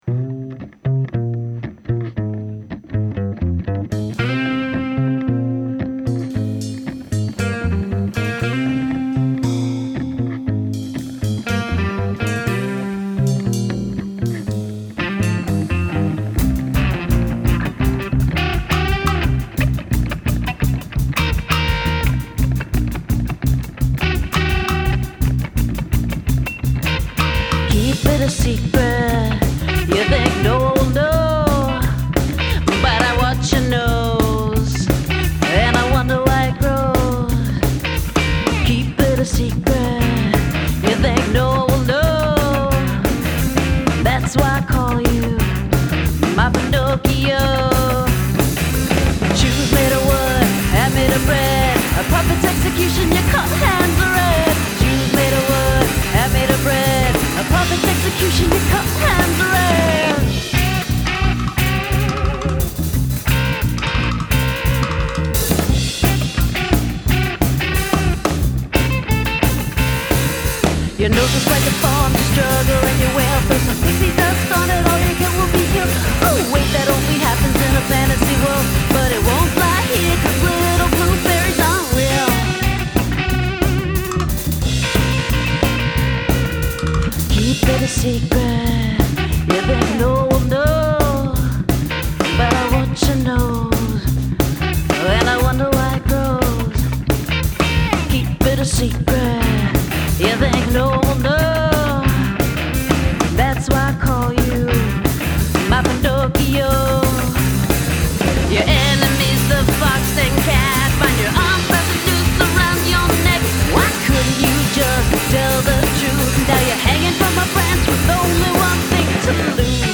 Recorded live at Melrose Music Studios, Hollywood, CA.